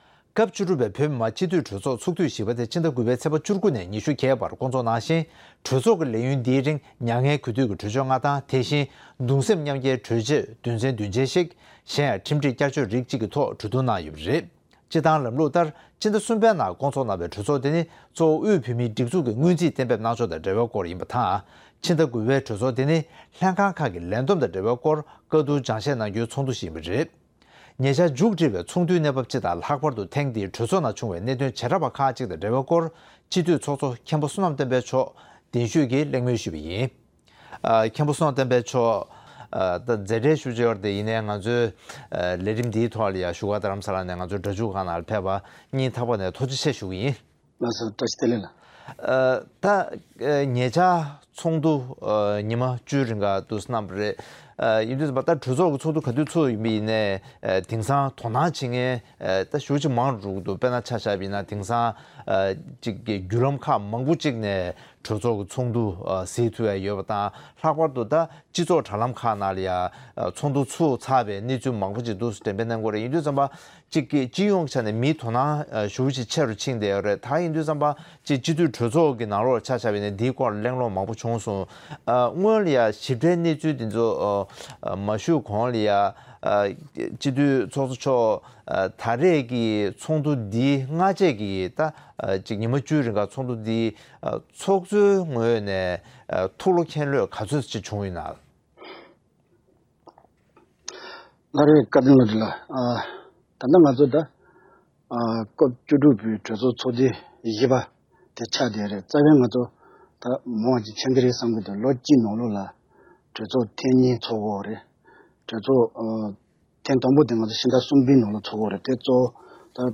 སྐབས་བཅུ་དྲུག་པའི་སྤྱི་འཐུས་གྲོས་ཚོགས་སྐབས་བཞིའི་གནད་དོན་ཐད་གླེང་མོལ།